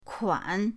kuan3.mp3